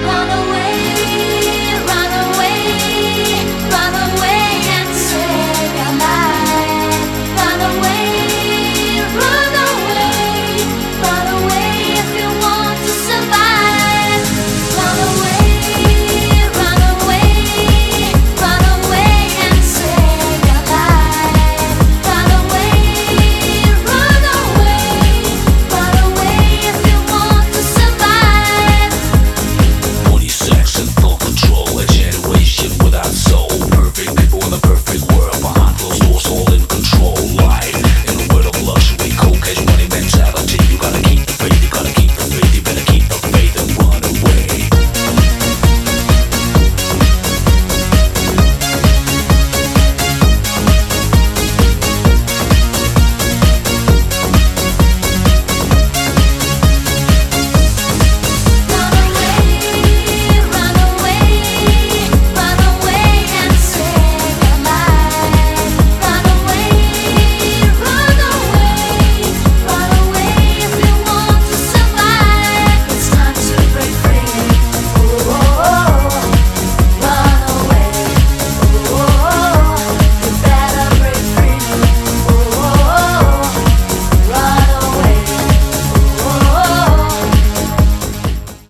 BPM131
Audio QualityPerfect (High Quality)
The song is ripped straight from the original album CD.